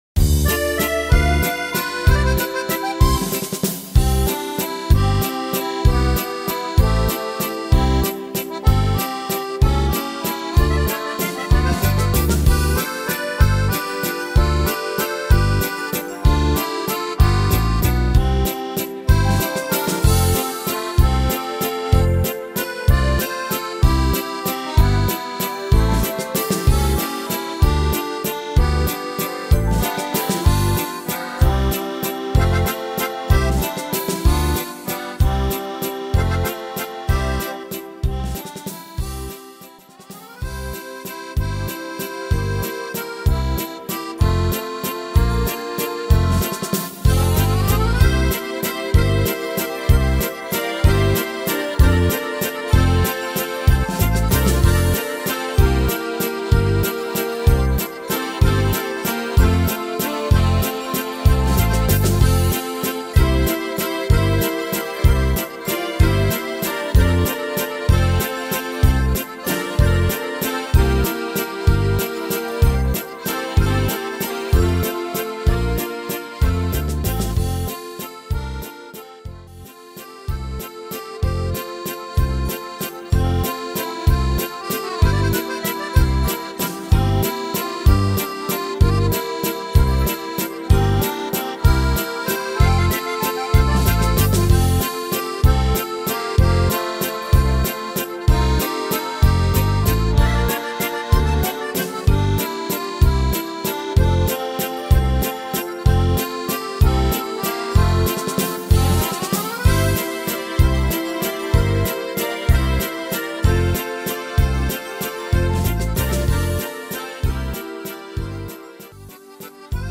Tempo: 190 / Tonart: C-Dur